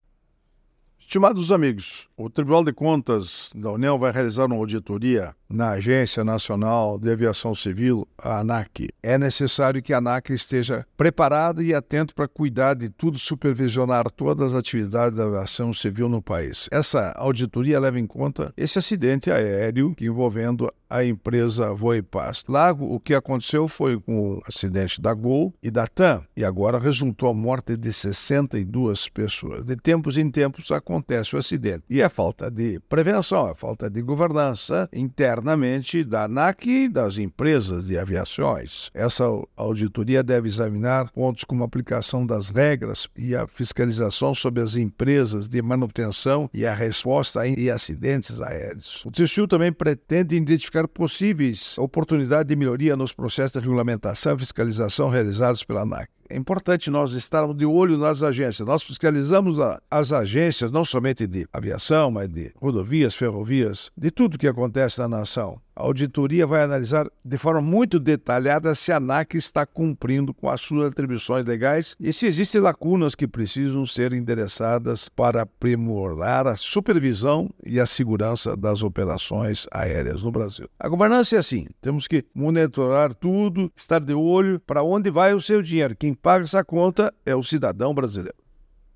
É o assunto do comentário desta sexta-feira (06/09/24) do ministro Augusto Nardes (TCU), especialmente para OgazeteitO.